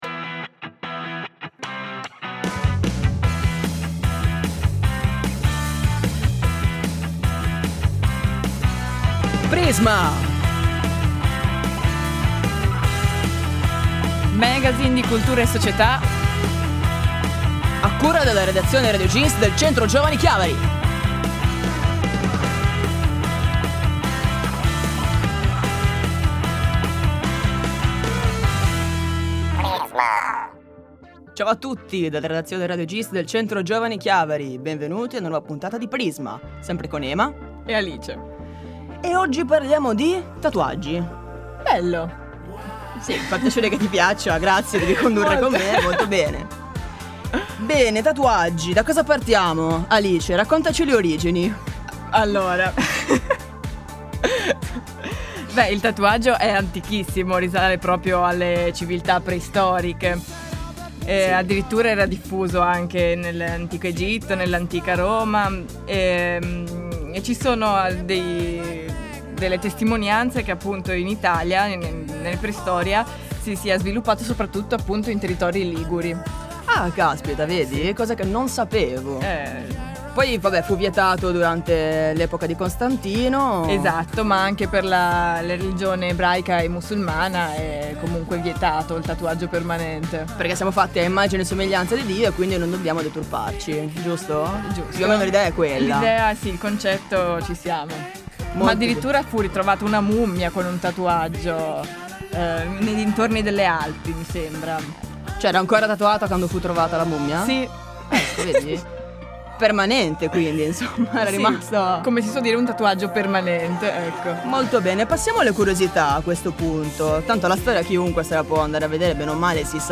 Prisma è un magazine di cultura e società